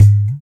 50 LO DRUM-R.wav